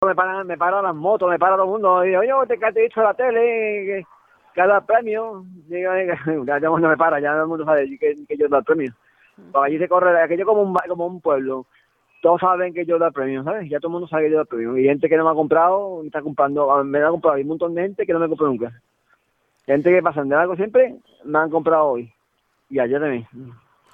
Él habla rápido, y a veces es difícil seguirle en sus expresiones al más puro estilo de la tierra.